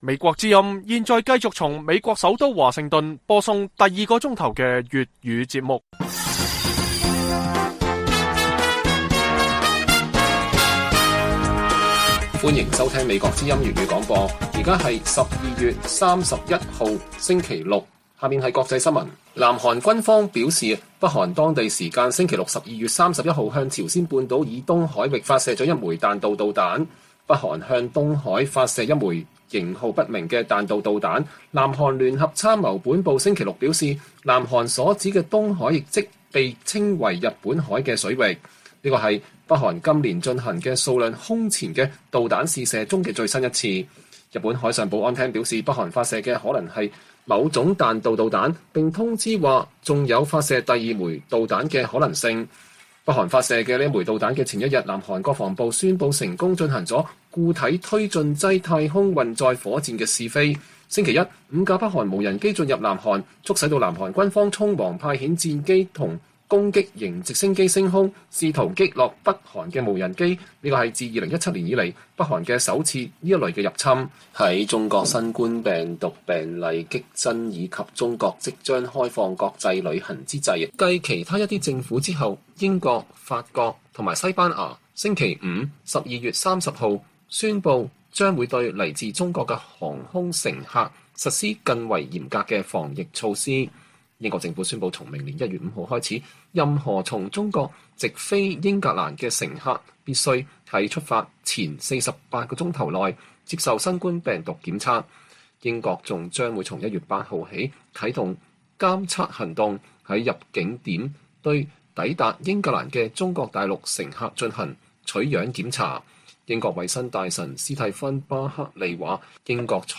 粵語新聞 晚上10-11點 : 中文推特用戶熱議美中關係與台灣問題